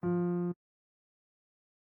CHOMPStation2/sound/piano/E#4.ogg